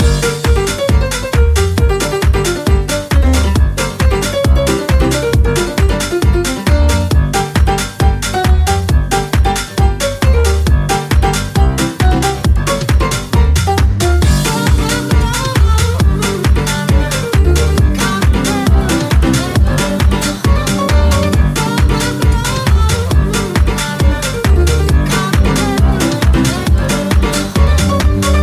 Genere: pop,house, deep, club, remix